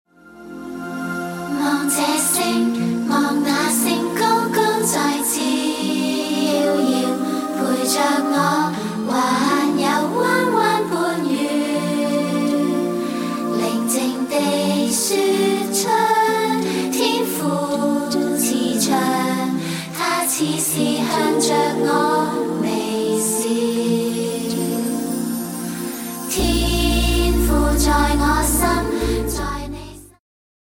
充滿動感和時代感
(有伴奏音樂版本)